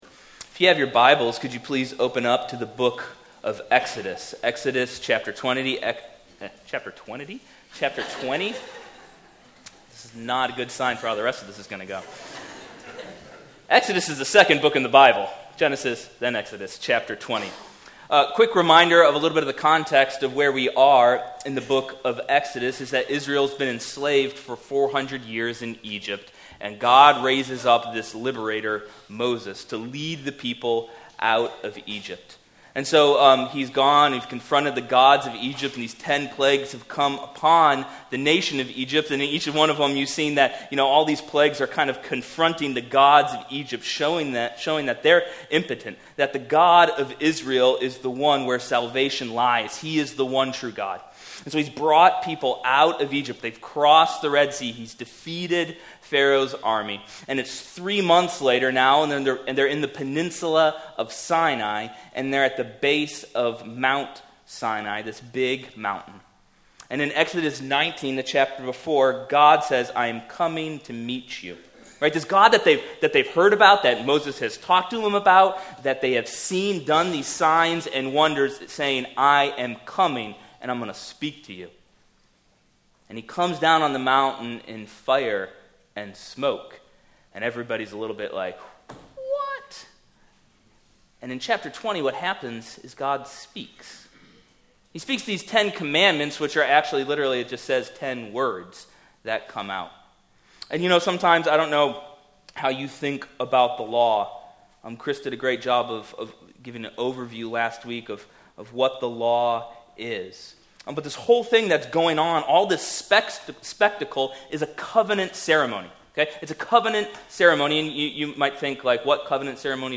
Passage: Exodus 20:1-6 Service Type: Weekly Sunday